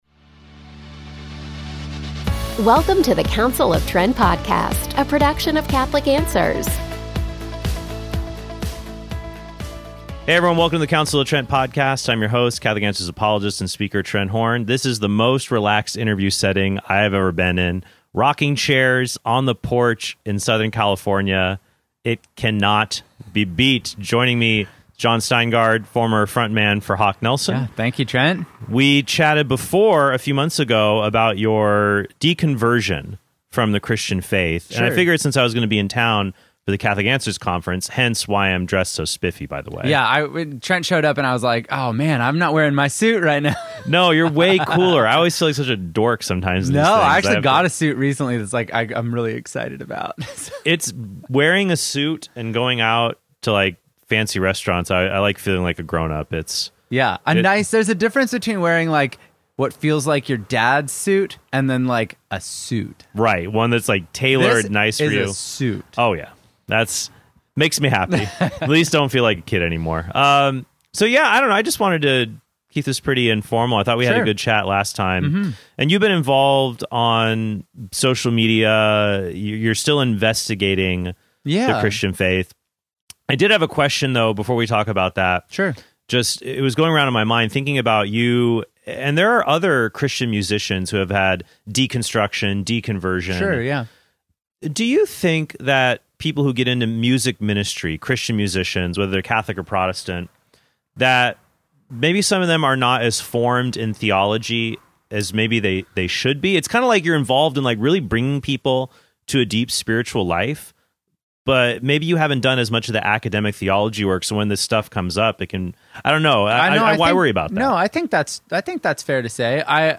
This is the most relaxed interview setting I have ever been in. Rocking chairs on the porch in Southern California.